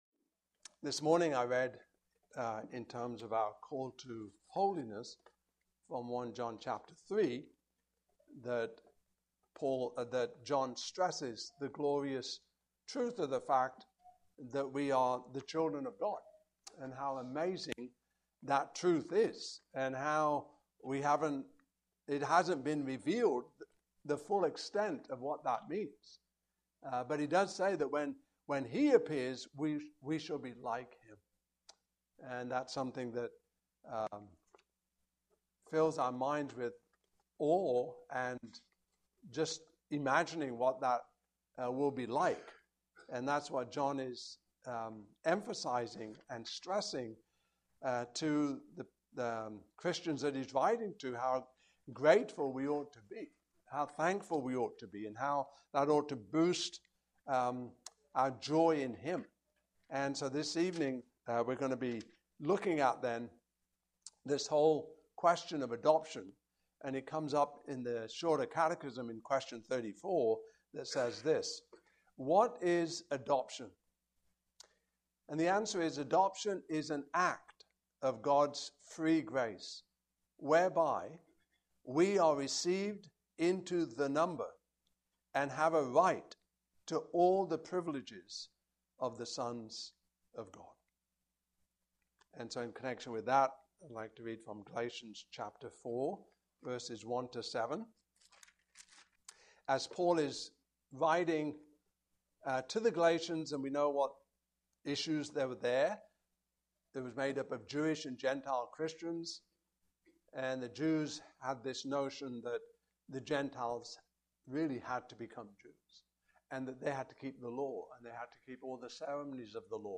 Westminster Shorter Catechism Passage: Galatians 4:1-7 Service Type: Evening Service Topics